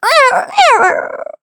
Taily-Vox_Damage_kr_03.wav